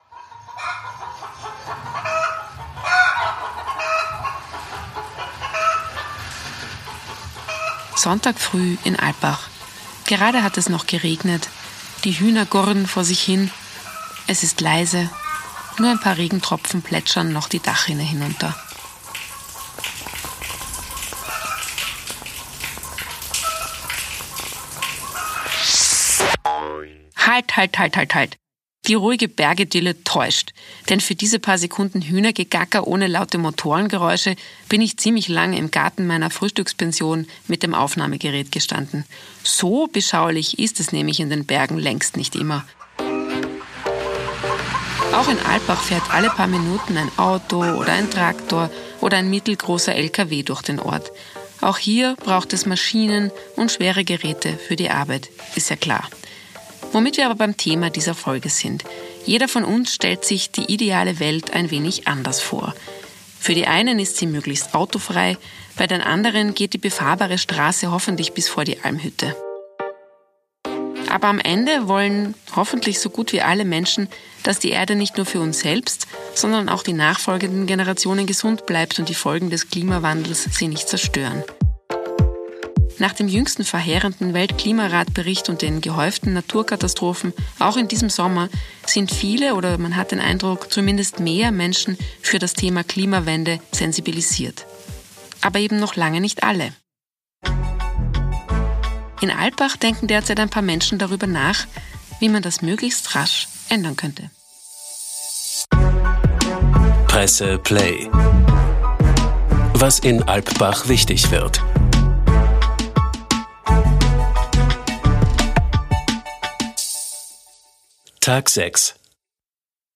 Was denken, was wollen die Climate-Change-Leaders, was soll am Ende dieses Experiments herauskommen? Eine Hör-Reportage.